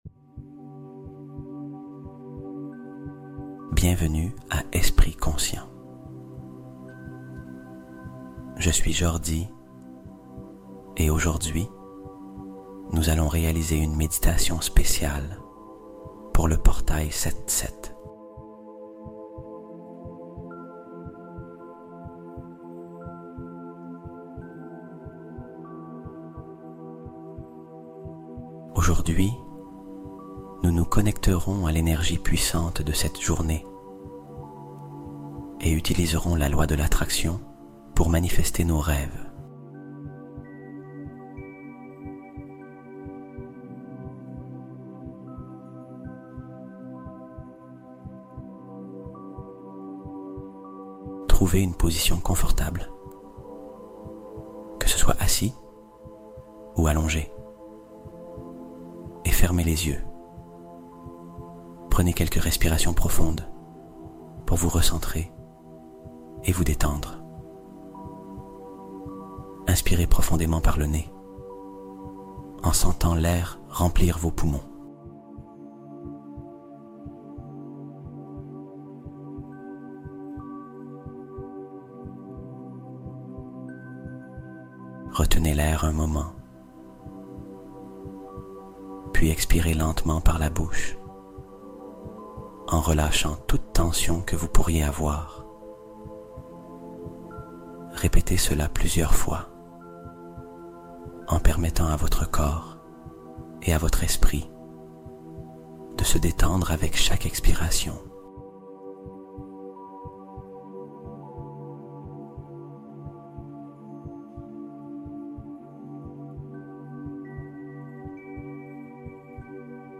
Le Portail 7/7 S'Ouvre MAINTENANT : Manifeste Tes Rêves Avec La Fréquence Sacrée 1111 Hz